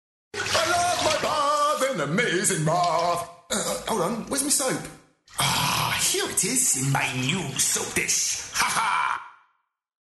Character Voice Over Artists | Voice Fairy
Young adult age range and ageless weird/funny voices.